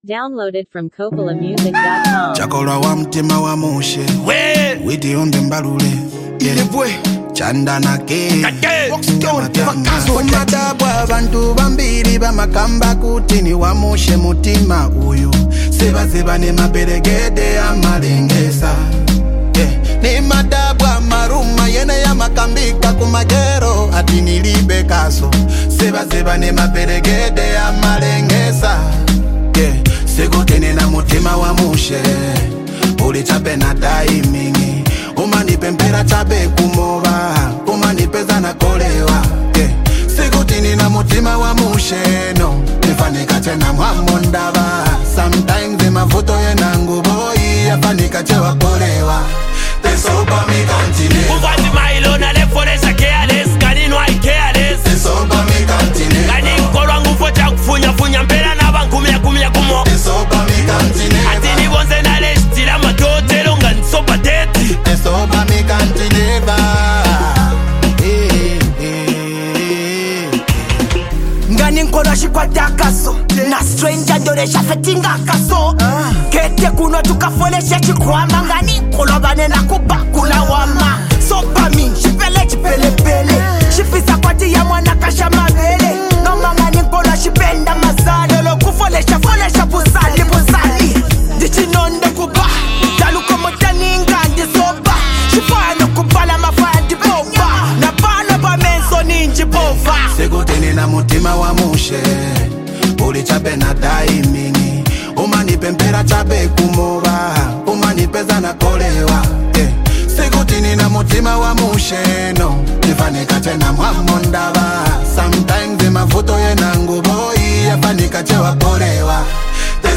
a powerful and emotional song
smooth and soulful touch
a catchy melodic vibe